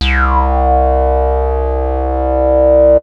15Bass11.WAV